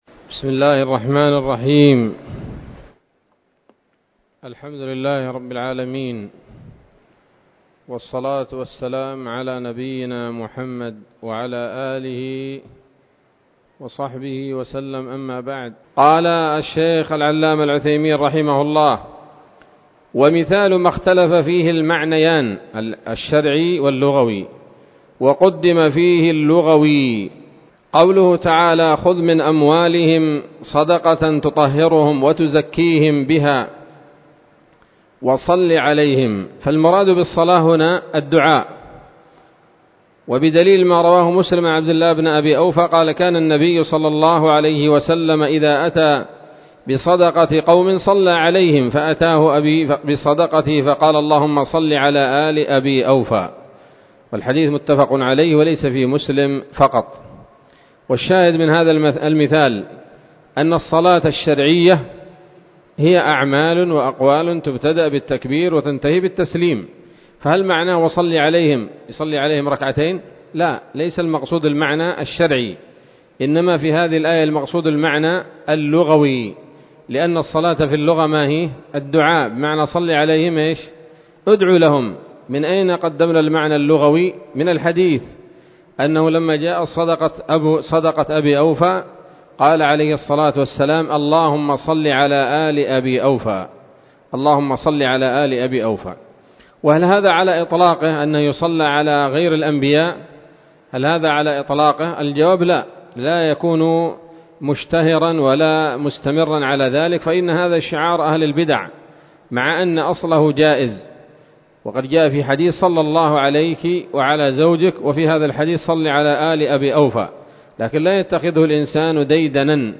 الدرس الحادي والعشرون من أصول في التفسير للعلامة العثيمين رحمه الله تعالى 1446 هـ